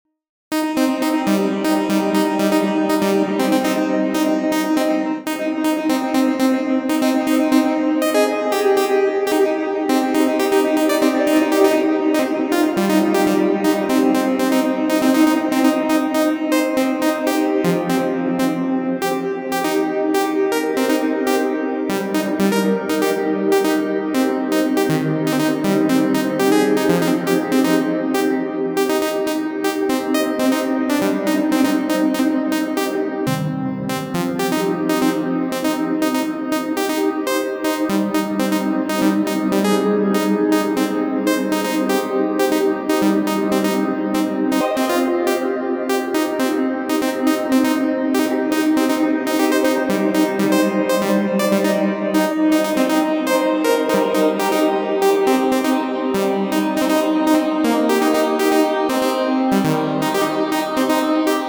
А вы можете сделать пример 5-10 секунд одной ноты , пилообразной формы ? можно будет понять